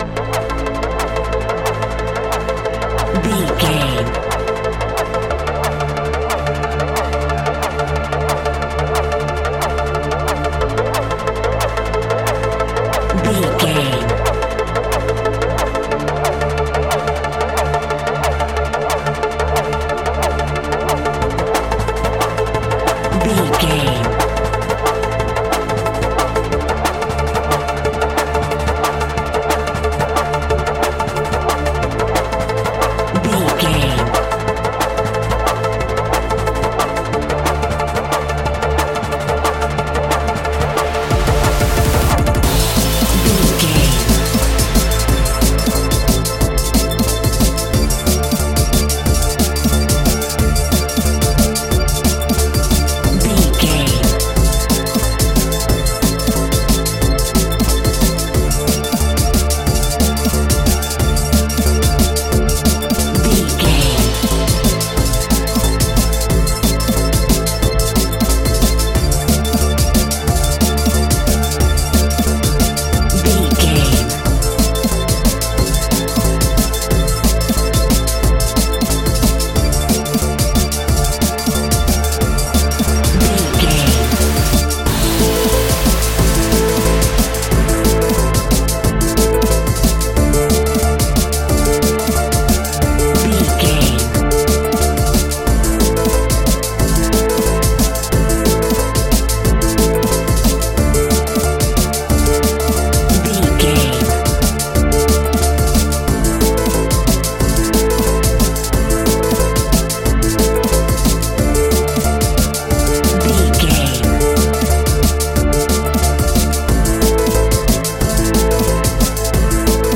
Aeolian/Minor
Fast
aggressive
dark
futuristic
frantic
synthesiser
drum machine
electric piano
darkstep
sub bass
Neurofunk